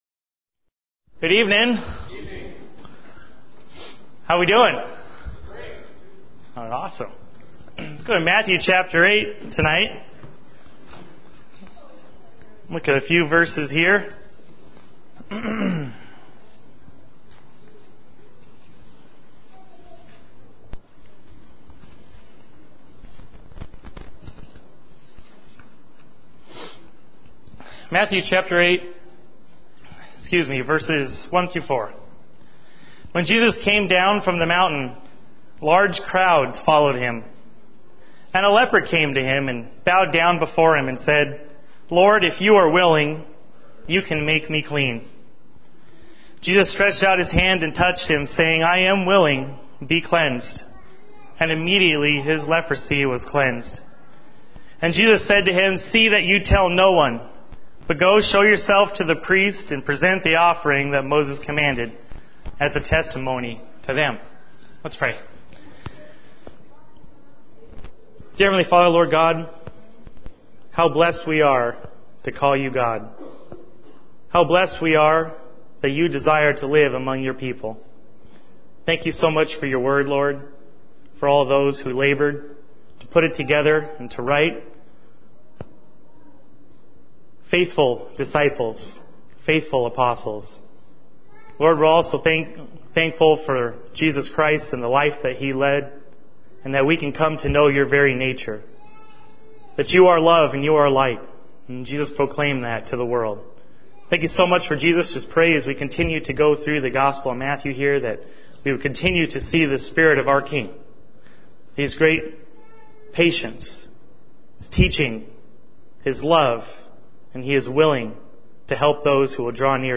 Series: Evening Messages